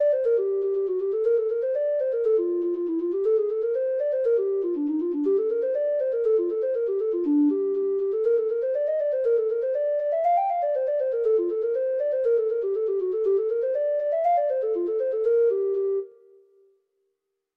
Traditional Sheet Music
Reels
Irish